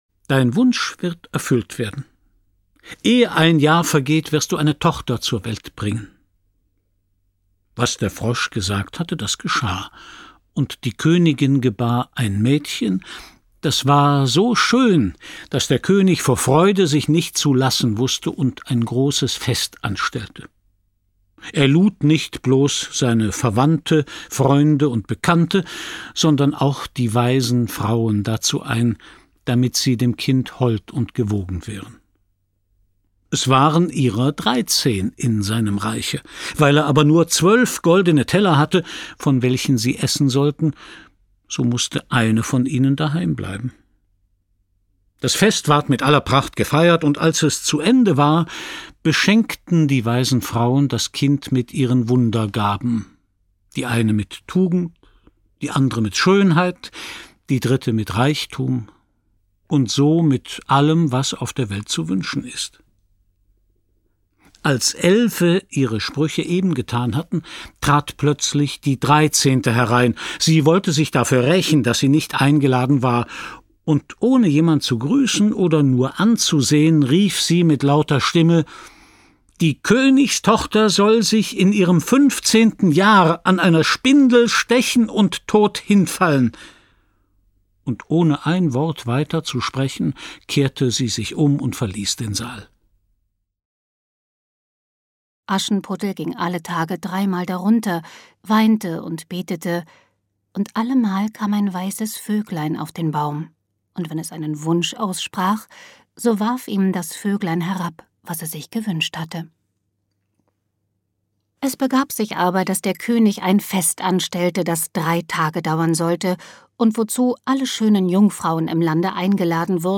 Ravensburger Die schönsten Kinder- und Hausmärchen ✔ tiptoi® Hörbuch ab 5 Jahren ✔ Jetzt online herunterladen!
Die_schoensten_Kinder-_und_Hausmaerchen-Hoerprobe.mp3